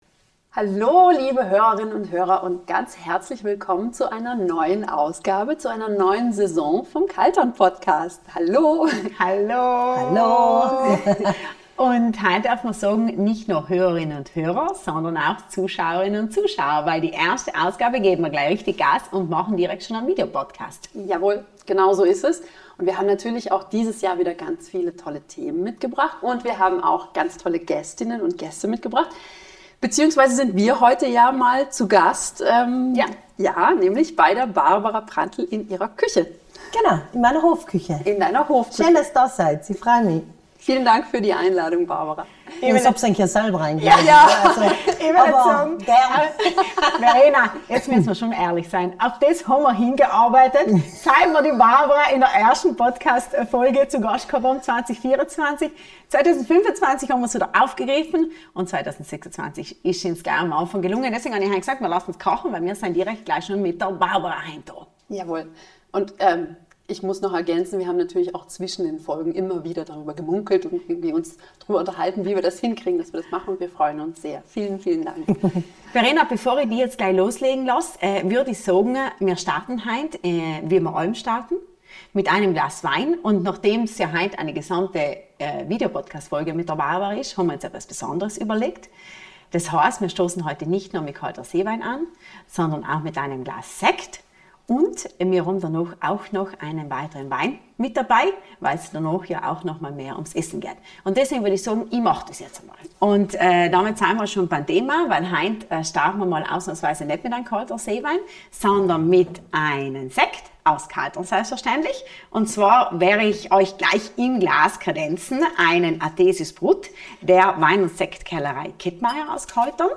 Dazu gibt’s gefüllte Polentakörbchen auf Blattsalaten … und viiiel Gelächter!